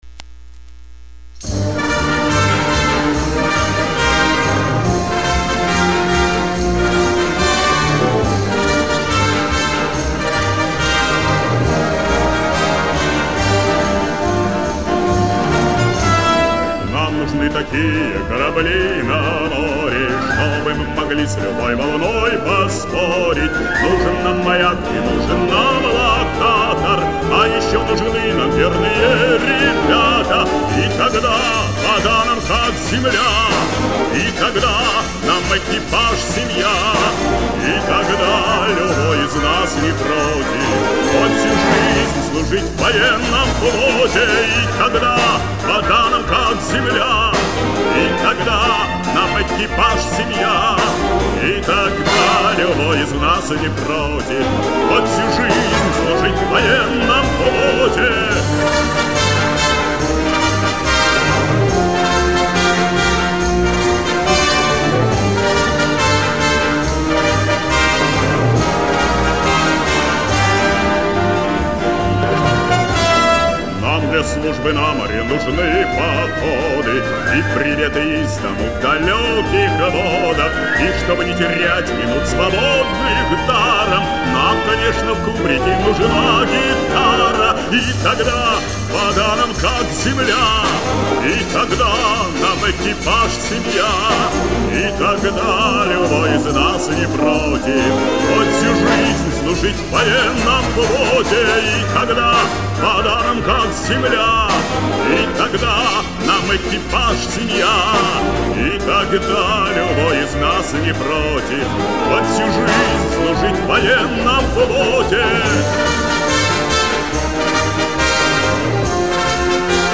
Прекрасная бодрая позднесоветская песня о службе в ВМФ СССР.